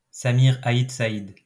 קובץ הגייה מושמעת ממיזם לינגואה ליברה .